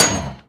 metalhit2.ogg